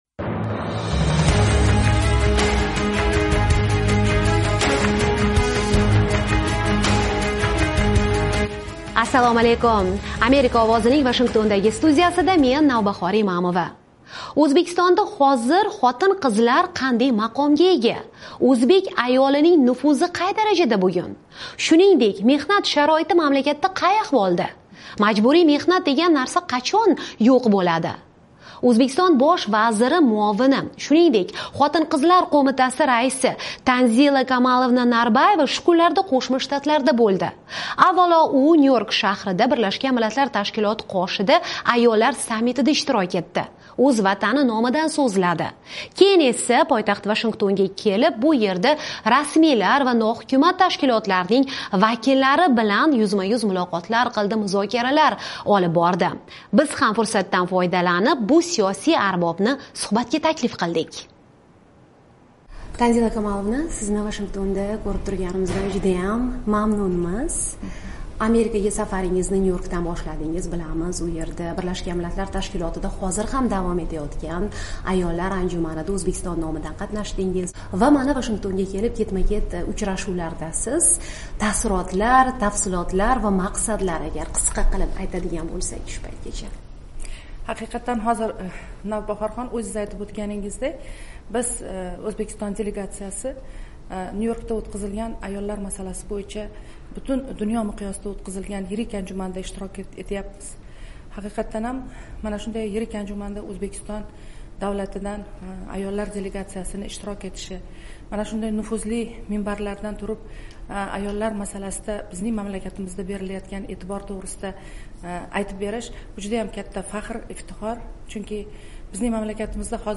O'zbekiston Bosh vaziri o'rinbosari bilan yuzma-yuz muloqot